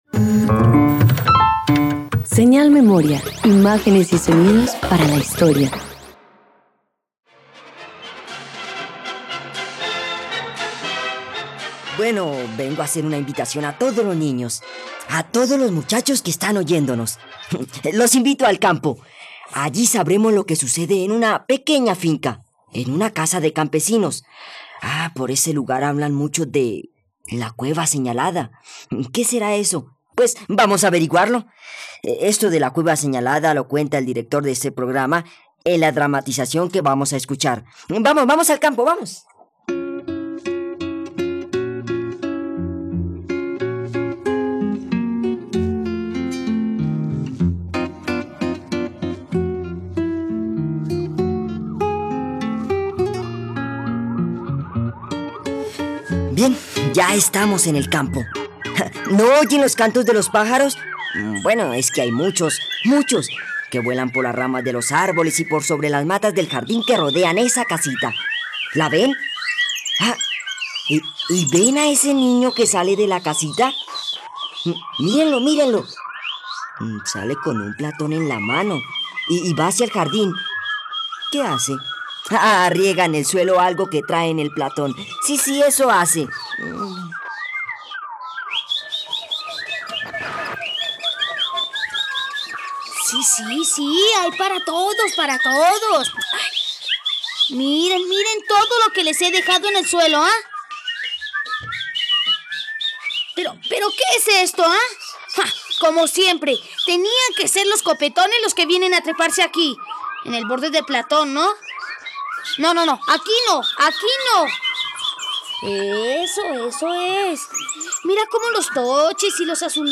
..Radioteatro. Escucha la adaptación de la obra "La cueva señalada" del director colombiano José Pulido Téllez en la plataforma de streaming RTVCPlay.